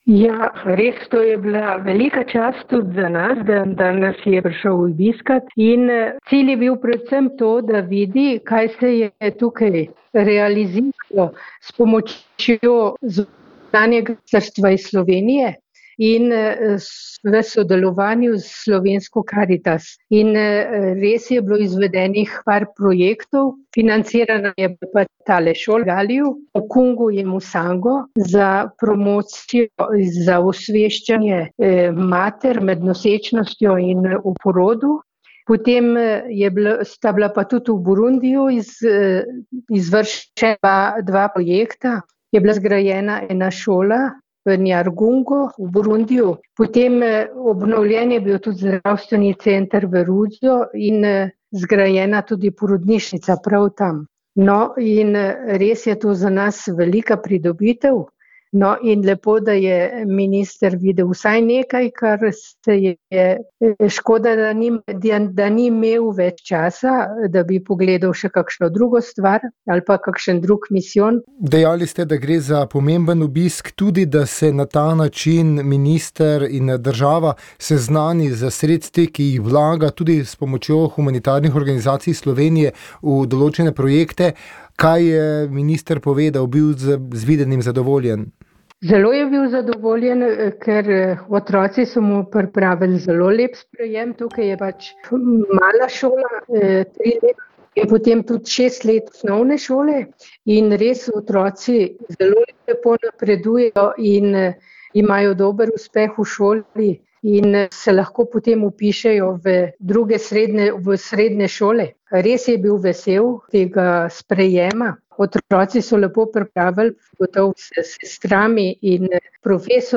Izjava misijonarke